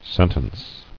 [sen·tence]